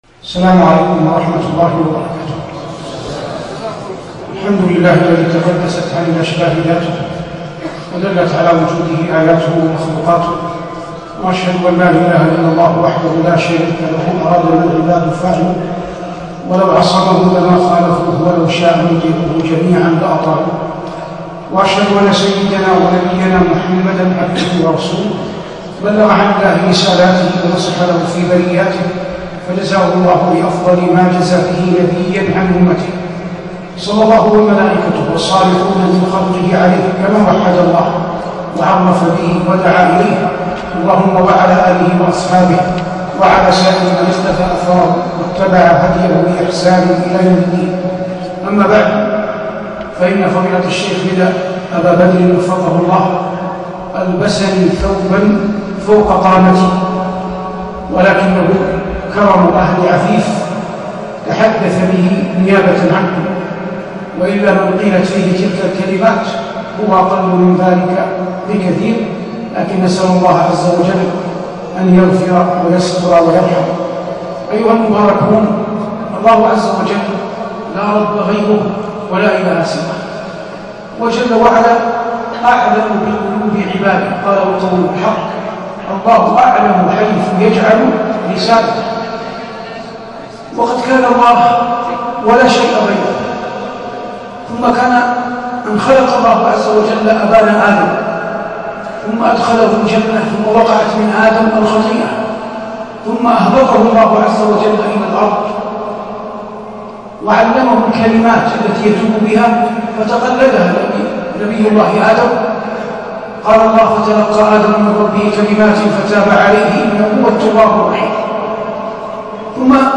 محاضرة بعنوان: "إن إبراهيم كان أمة" بجامع الأمير سلطان بمحافظة عفيف 1439/5/6هـ